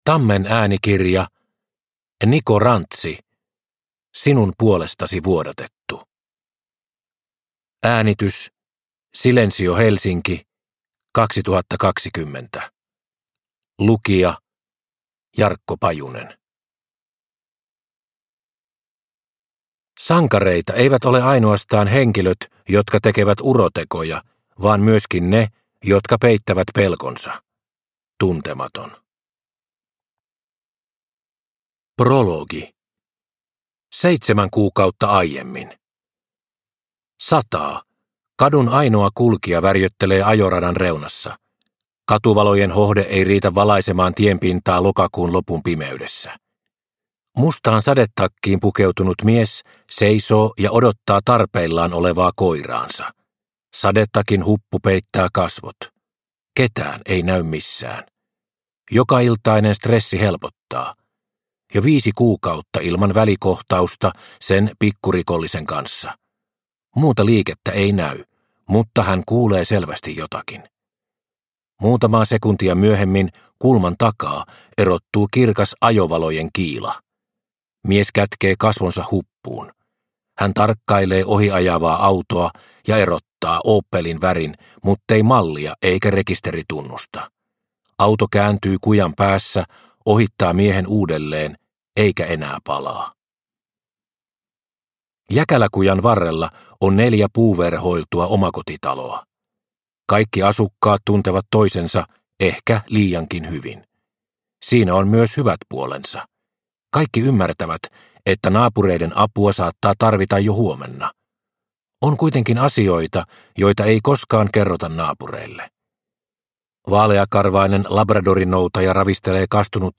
Sinun puolestasi vuodatettu – Ljudbok – Laddas ner